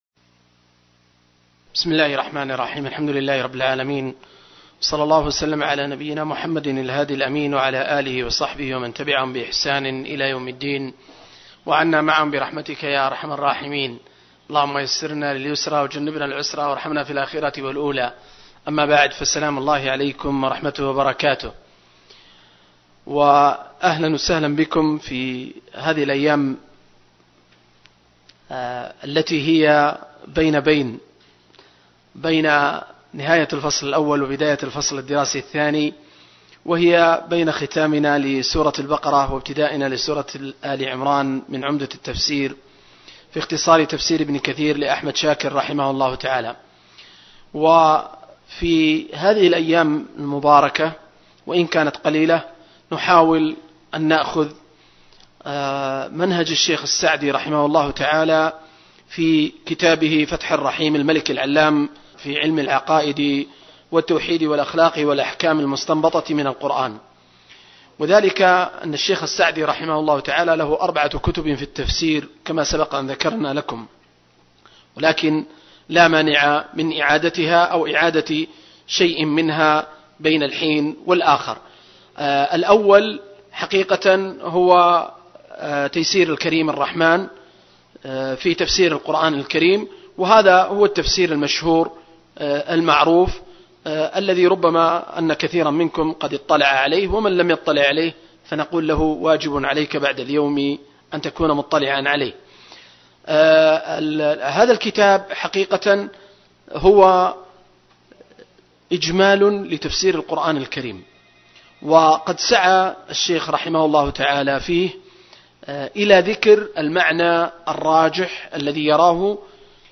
دورة علمية في قاعة الدروس والمحاضرات